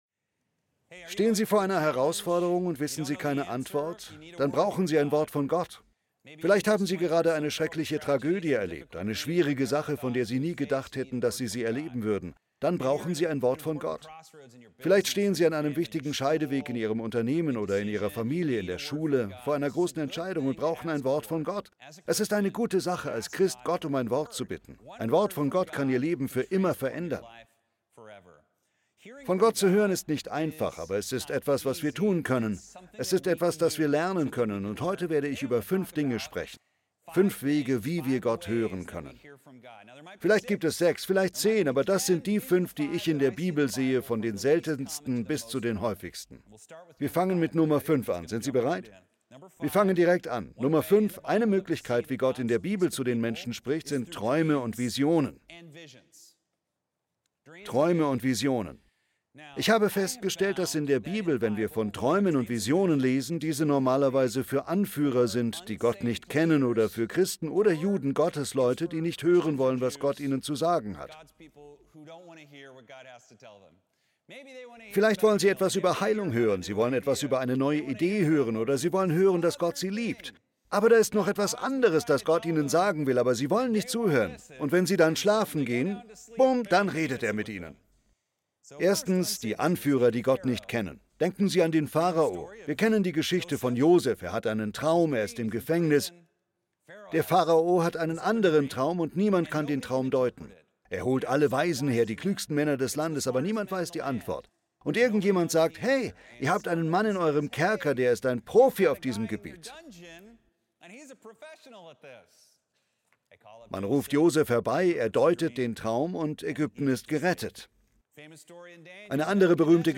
Gottesdienste Archiv - Hour of Power - die beste Stunde im Fernsehen